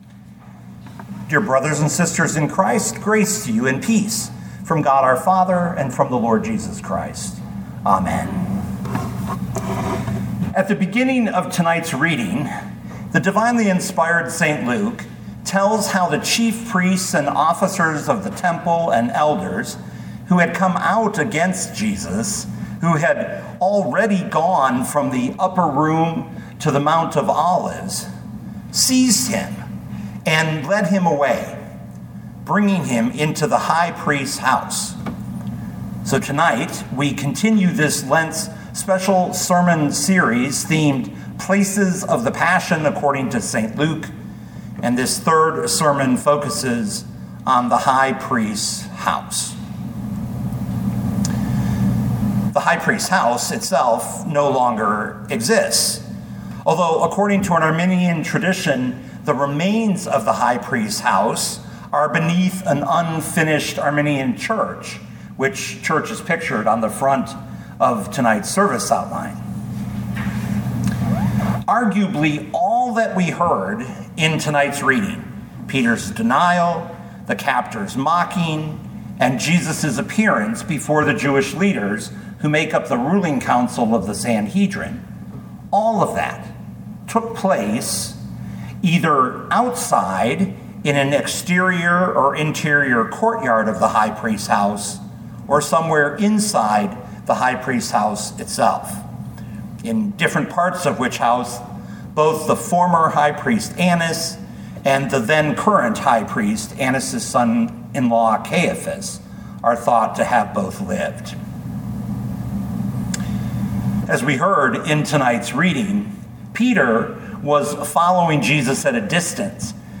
2025 Luke 22:54-71 Listen to the sermon with the player below, or, download the audio.